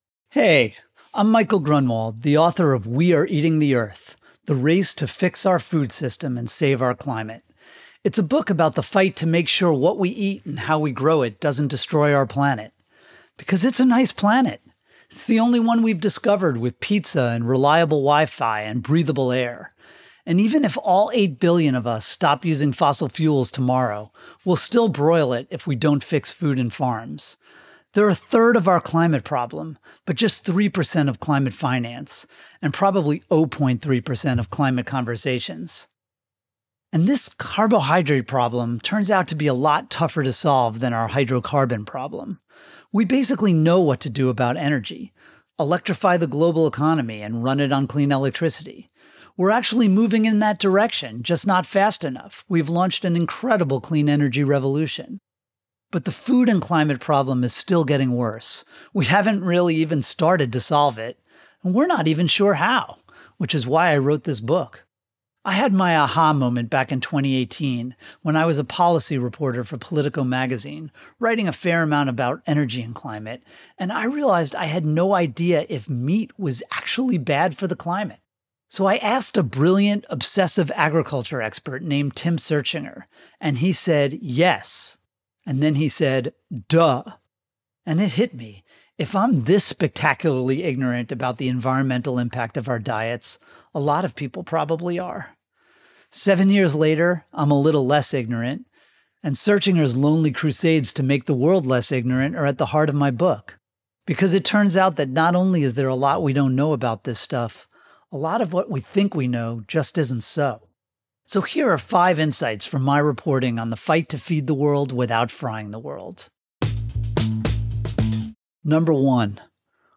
Listen to the audio version—read by Michael himself—below, or in the Next Big Idea App.